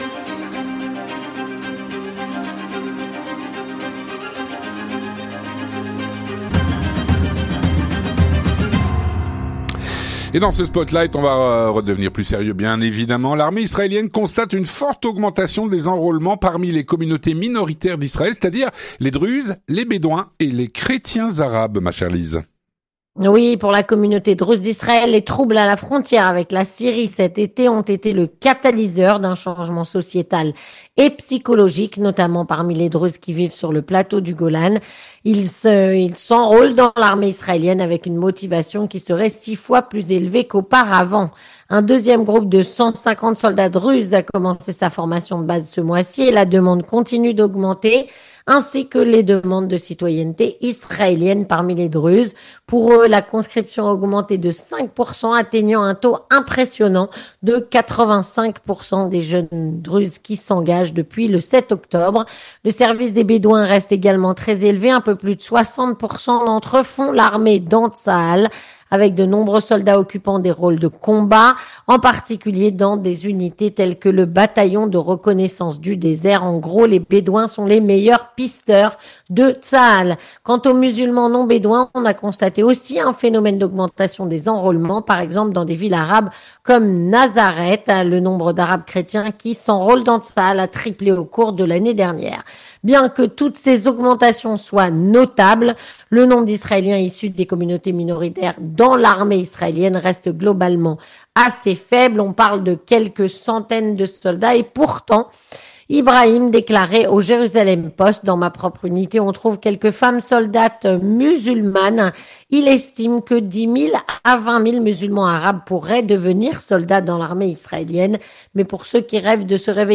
3. Les Chroniques de la Matinale
"Spotlight", une chronique